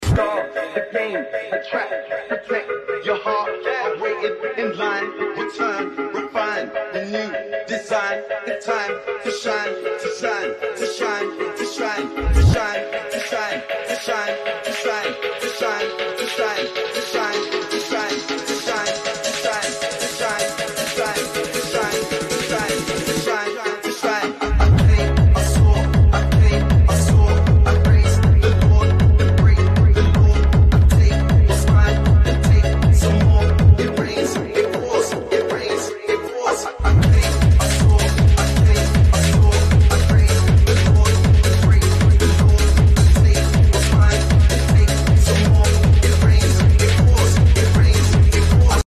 Massey Ferguson 8480 Straight Pipe Sound Effects Free Download
massey ferguson 8480 straight pipe